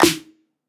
West MetroSnare (17).wav